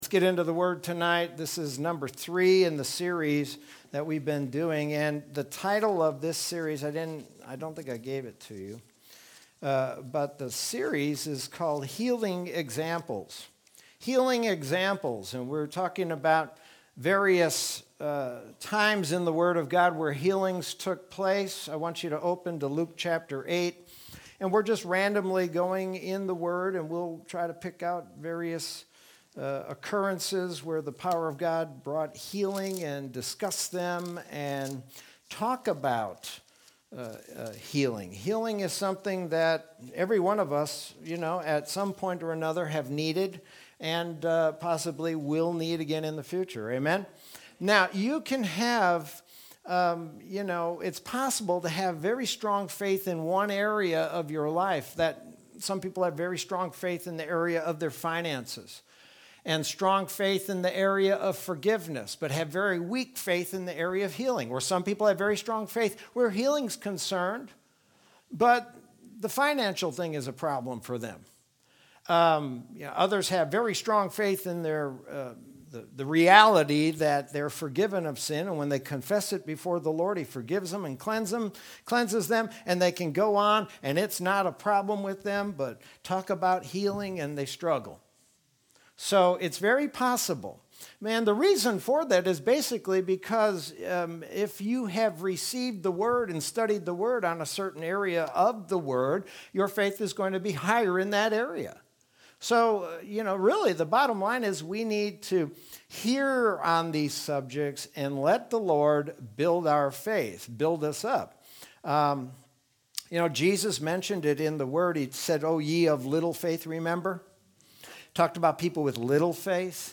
Sermon from Wednesday, January 20th, 2021.